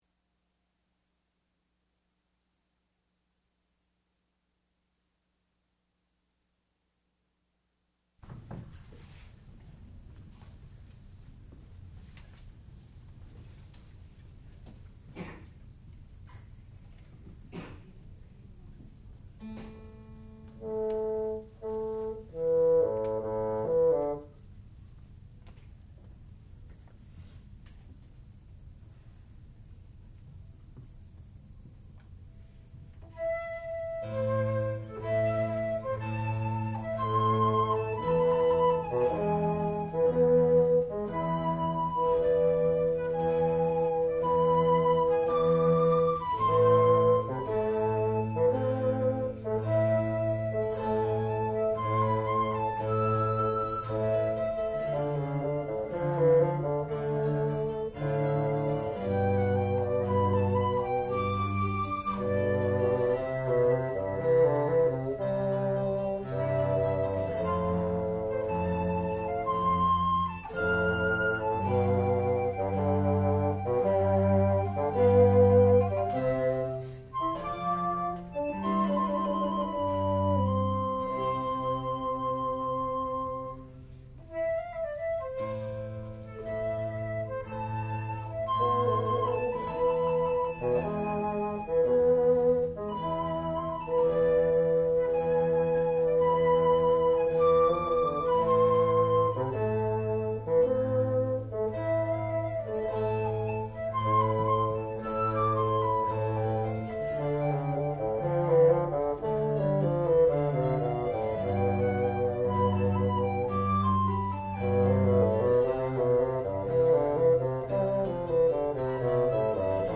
sound recording-musical
classical music
Junior Recital